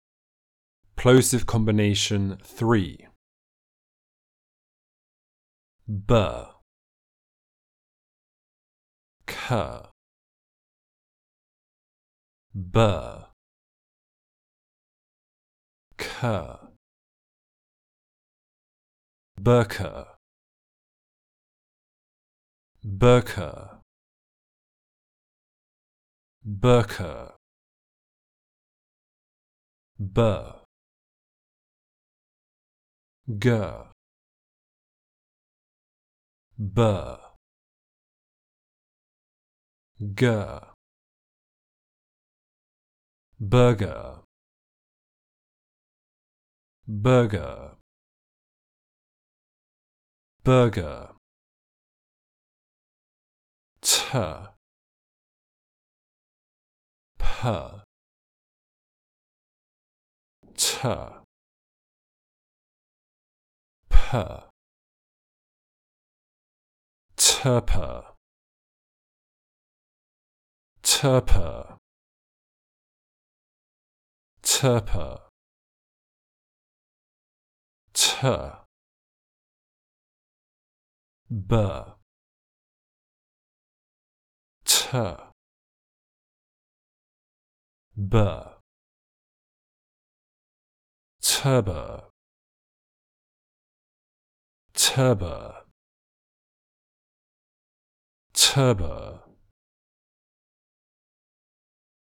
The Basic Building Blocks of Speech - Level 02 - British English Pronunciation RP Online Courses
Plosive combination 03
03_combination_plosive_03.mp3